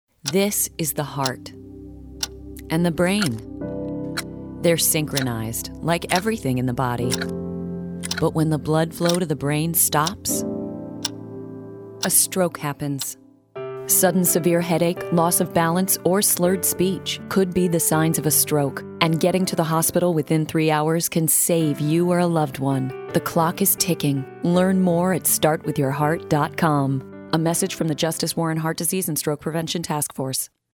The Clock is Ticking- 2013 Radio Ad